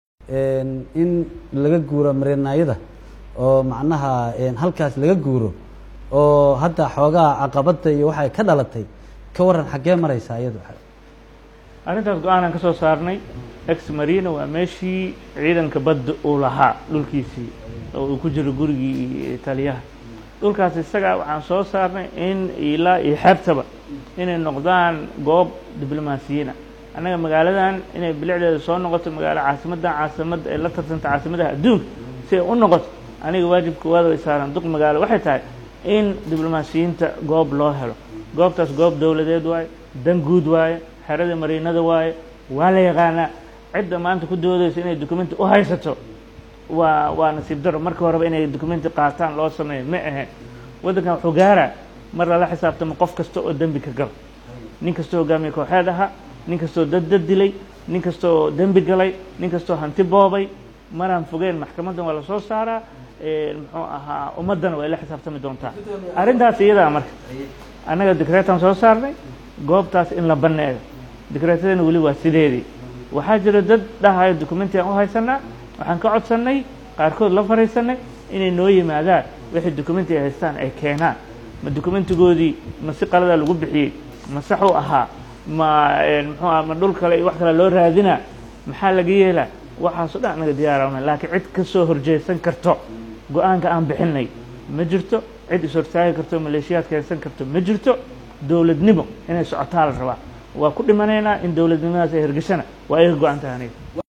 Injineer C/raxmaan Cumar Cismaan Yariisoow oo u waramay TV-ga Somali Cable ayaa sheegay ineysan u dul qaadaneynin in xoog lagu heysto dhul dowladeed, wuxuuna mar kale ku celiyay in la isaga guuro xerada oo dadka laga saarayo uu ku jiro Taliyaha Ciidanka Bada Soomaaliya.